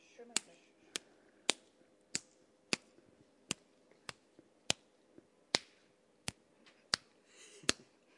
拳头 " 拳头在皮肤上3
描述：真实的皮肤冲孔
Tag: 一巴掌 一拳